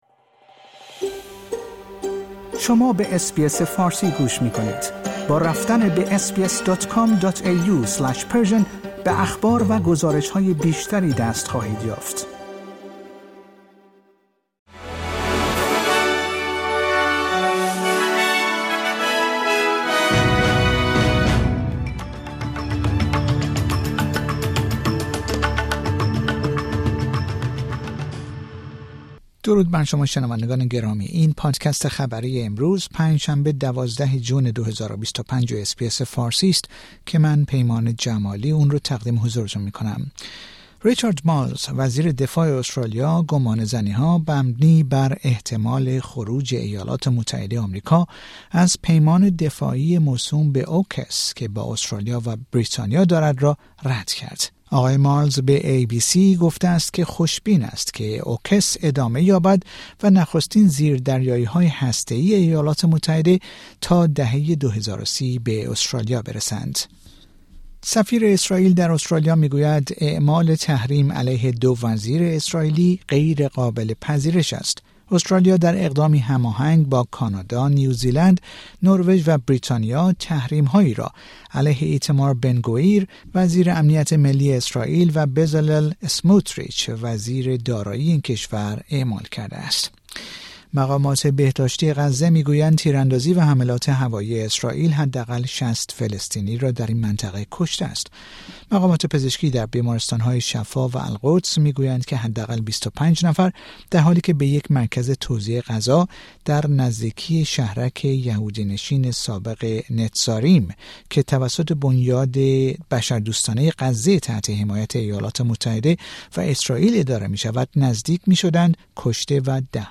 در این پادکست خبری مهمترین اخبار امروز پنج شنبه ۱۲ جون ارائه شده است.